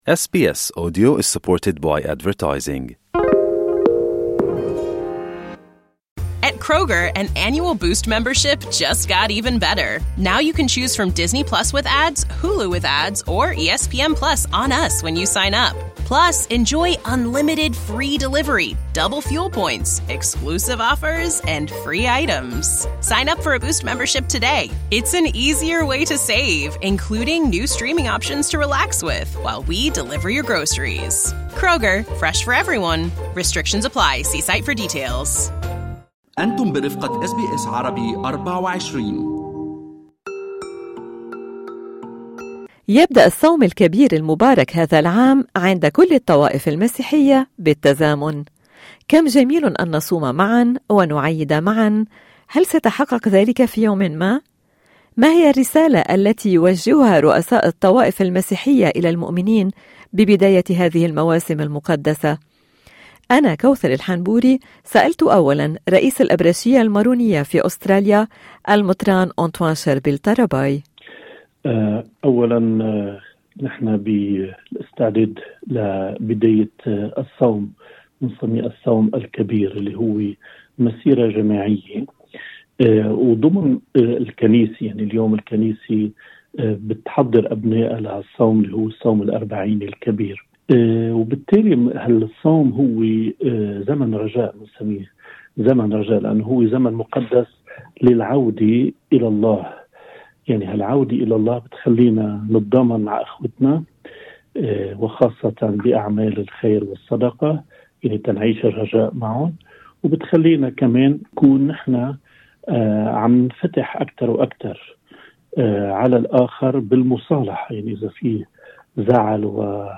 هذا العام تصوم الطوائف المسيحية معا: ما هي أهمية الصوم هل هو ألزامي في الكنيسة وما الفرق بين الصوم و"القطاعة" ومتى سيصبح العيد موحدا بشكل نهائي عند الطوائف المسيحية: أس بي أس عربي تحدثت الى راعي الأبرشية المارونية في أستراليا أنطوان شربل طربيه ،وراعي أبرشية الروم الملكيين الكاثوليك لأستراليا ونيوزلندا روبير رباط، والى راعي أبرشية الروم الأرثوذكس باسيليوس قدسية وراعي أبرشية الطائفة الكلدانية لأستراليا ونيوزلندا مار أميل نونا كل التفاصيل في الملف الصوتي أعلاه أكملوا الحوار على حساباتنا على فيسبوك وتويتر وانستغرام.